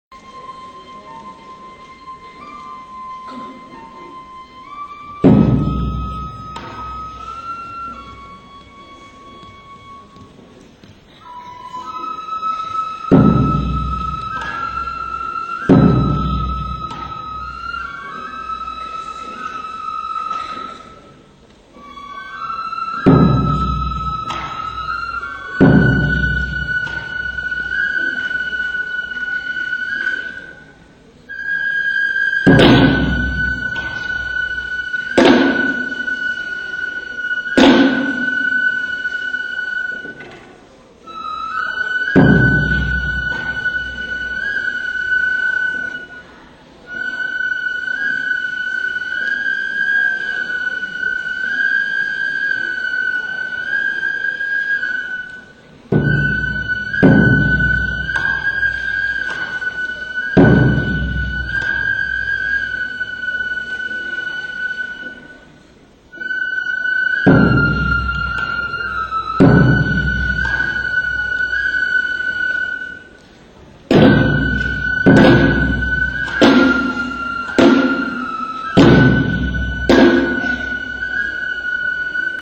尾張の山車囃子～知多
道行きの曲